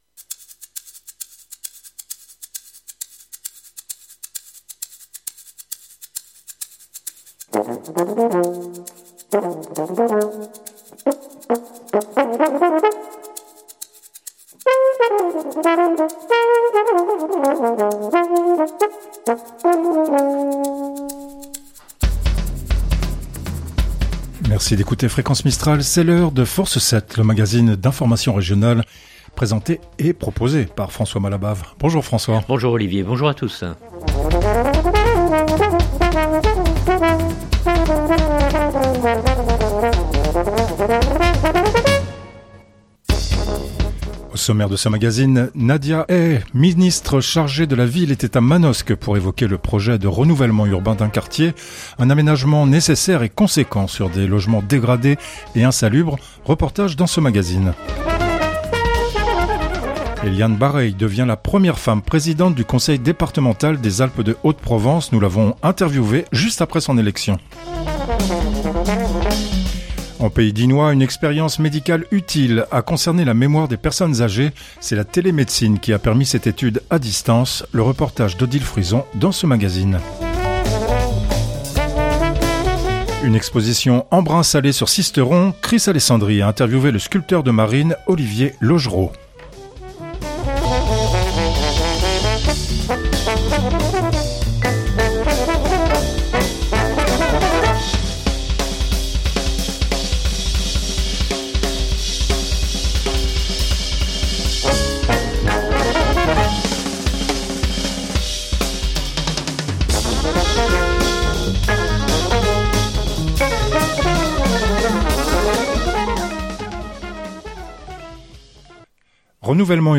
Reportage dans ce magazine. Eliane Barreille devient la première femme présidente du Conseil départemental des Alpes de Haute-Provence. Nous l’avons interviewée juste après son élection. En pays dignois, une expérience médicale utile a concerné la mémoire des personnes âgées.